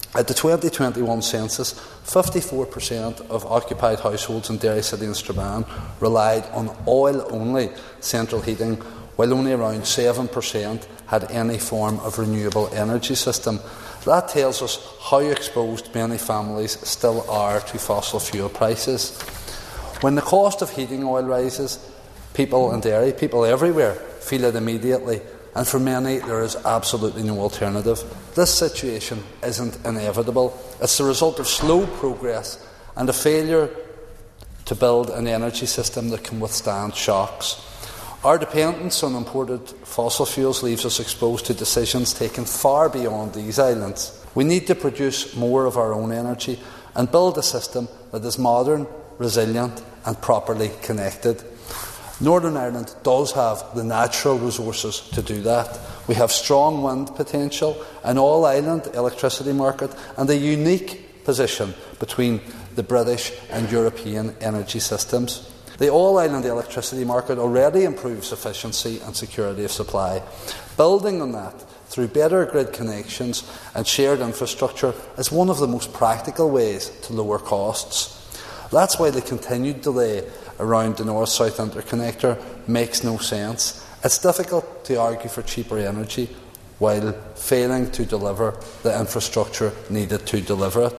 Mr Durkan told MLAs there are potential alternatives that need to be pursued……….